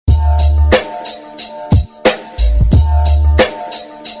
Музыкальный хостинг: /Рэп
freestyle